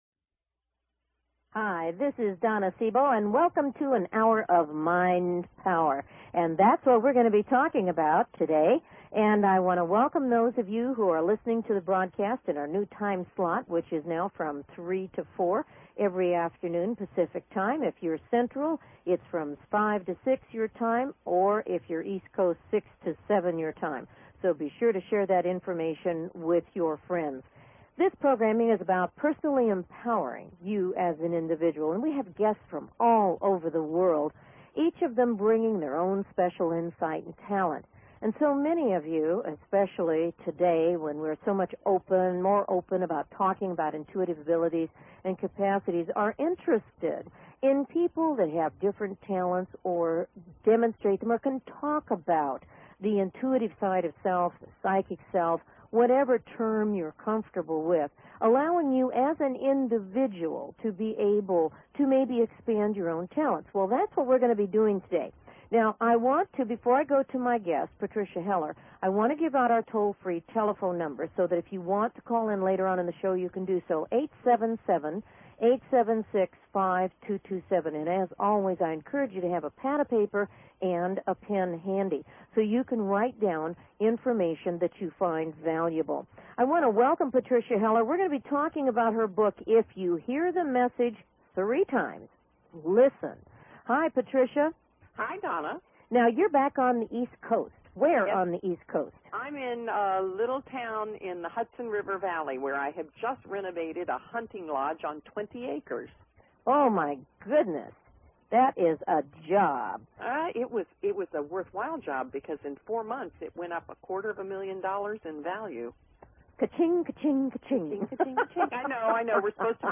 Her interviews embody a golden voice that shines with passion, purpose, sincerity and humor.